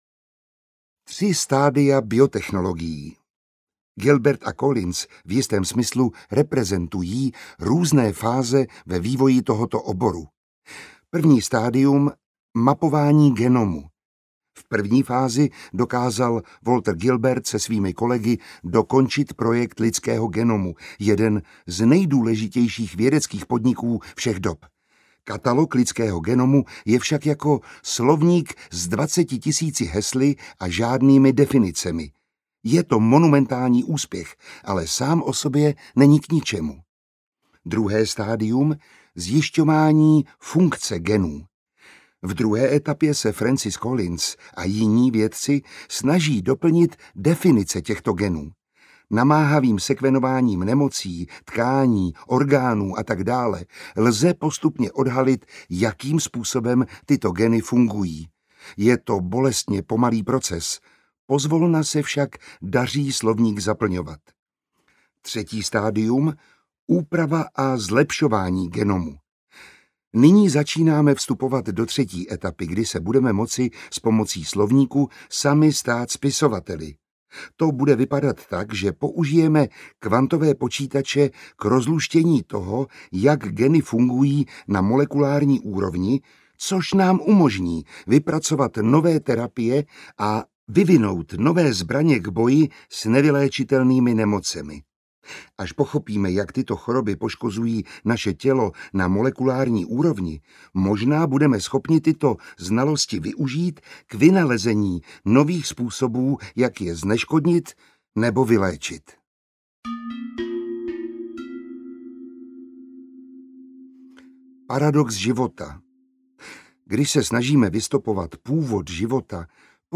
audiokniha
Čte: Miroslav Táborský